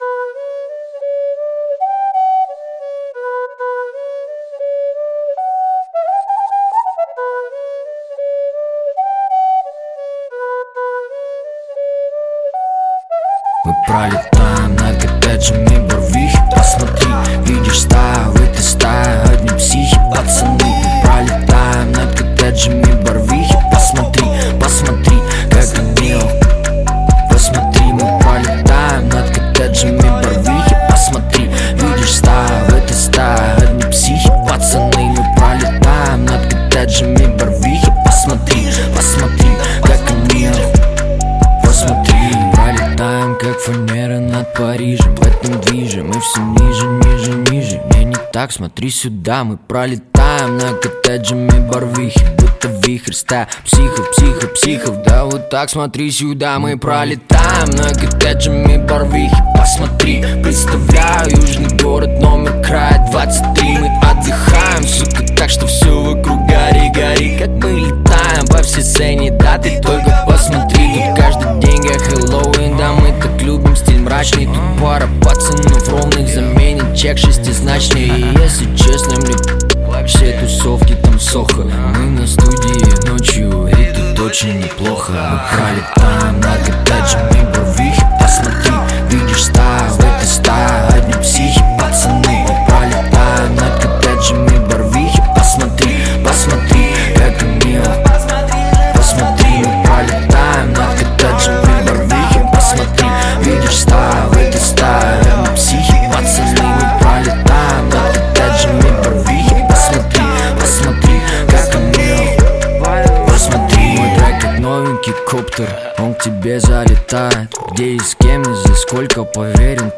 Жанр: Русский рэп / Хип-хоп